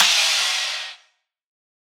Crashes & Cymbals
Crash Quasi.wav